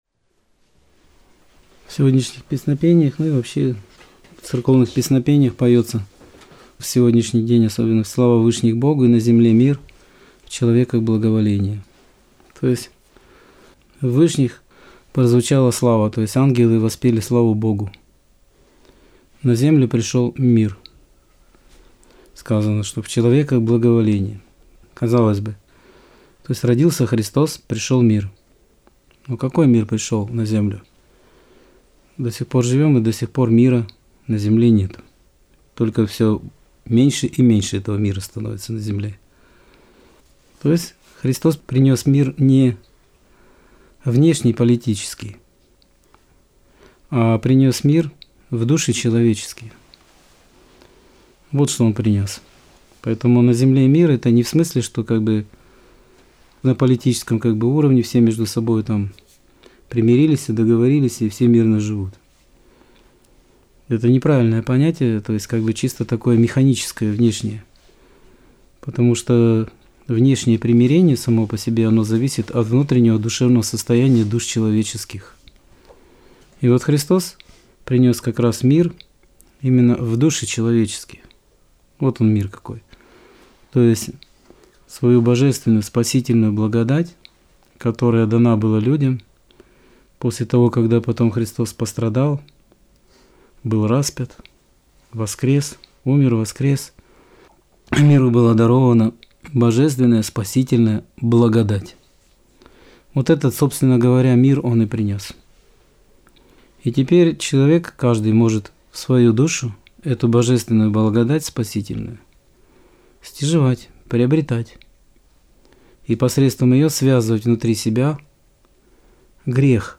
Аудио-проповедь 7.01.2022